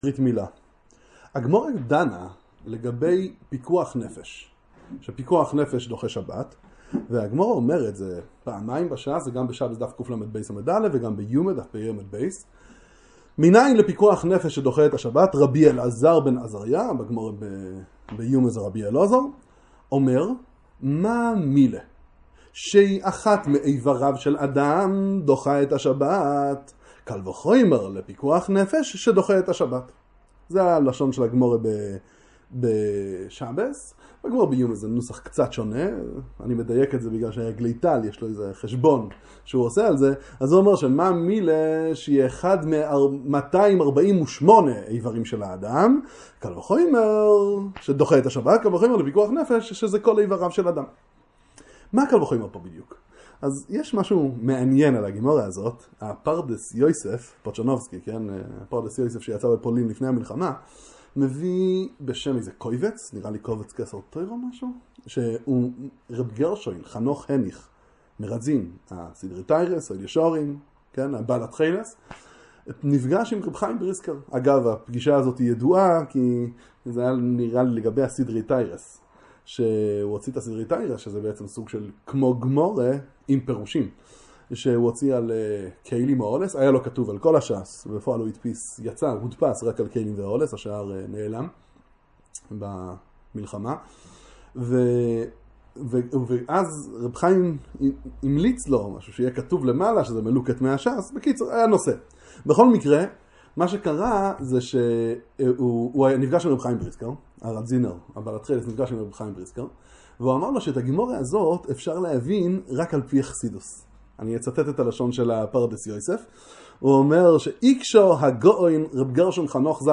דרשה לברית מילה